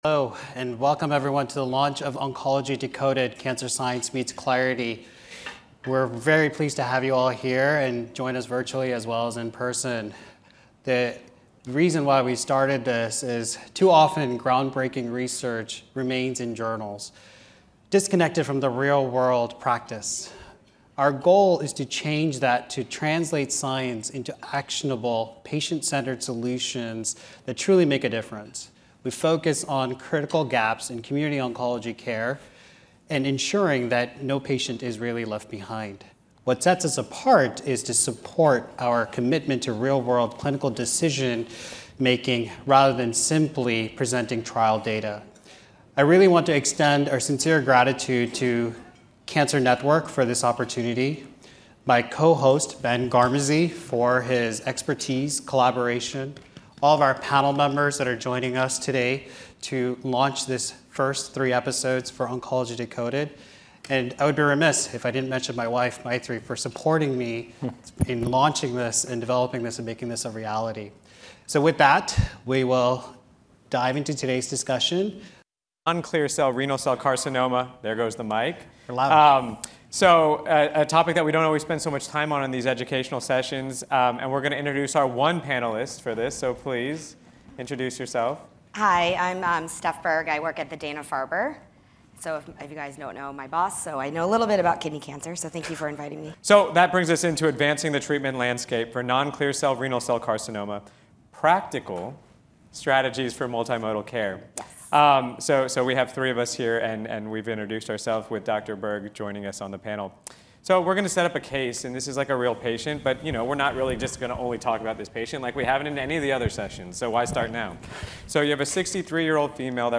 They discuss how neurodiverse traits impact daily routines, communication and self-advocacy, sharing practical tips and personal insights. Tune in for a powerful conversation on resilience, …